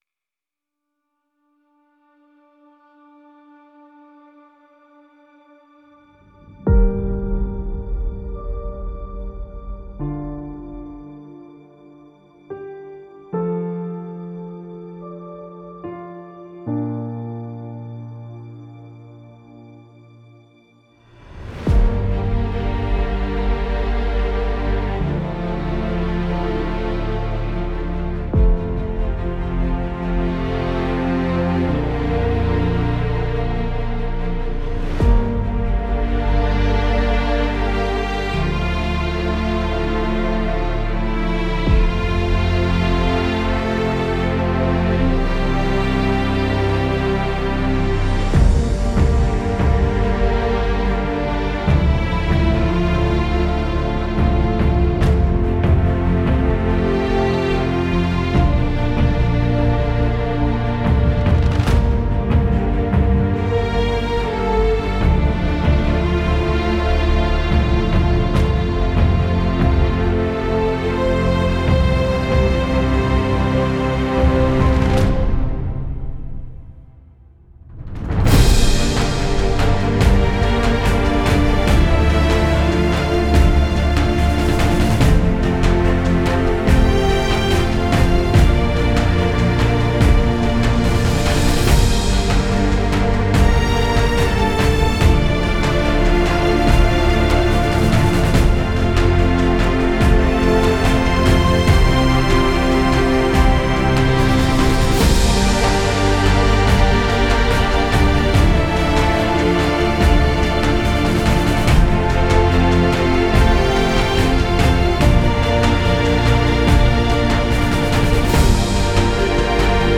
Cinematic, post-rock music for tv/film
Emotional Orchestral